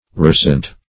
Search Result for " rousant" : The Collaborative International Dictionary of English v.0.48: Rousant \Rous"ant\, a. (her.)
rousant.mp3